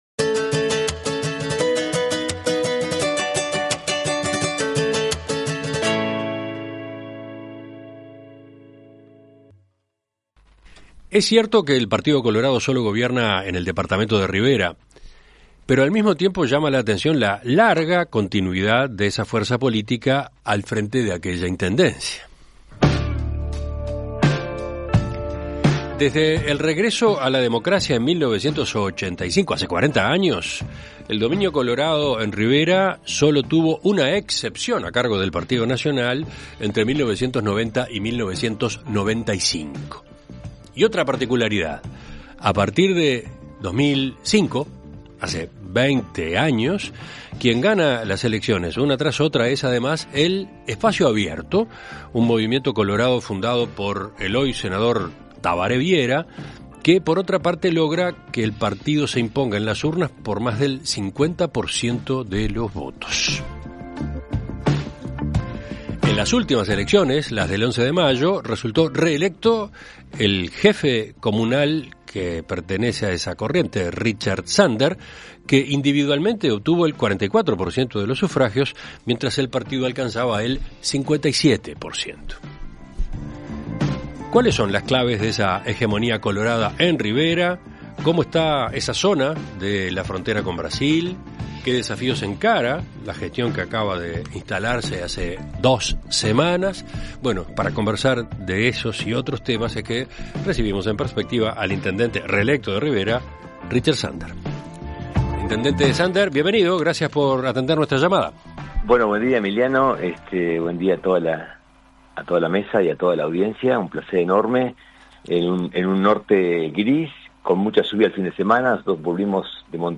En Perspectiva Zona 1 – Entrevista Central: Richard Sander - Océano
Conversamos con Richard Sander, intendente de Rivera, el único departamento donde gobierna el Partido Colorado desde hace 30 años.